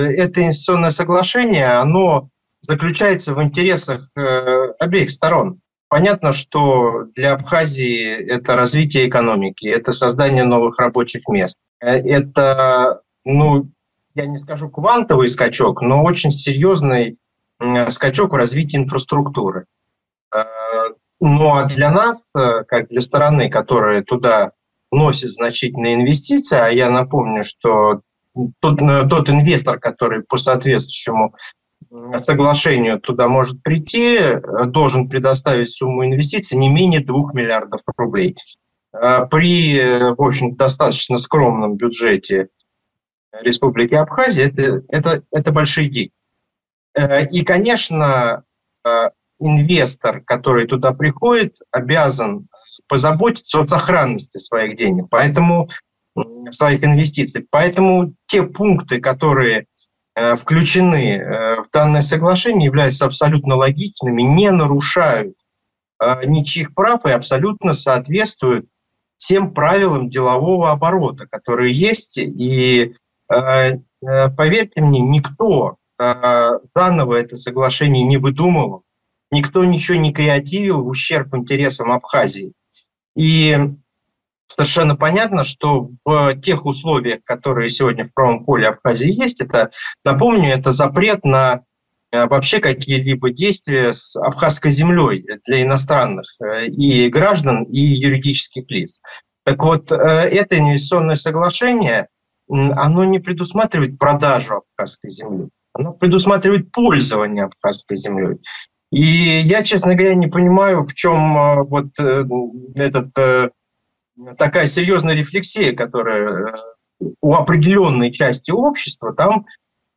Радио